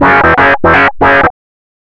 RIFFSYNT02-L.wav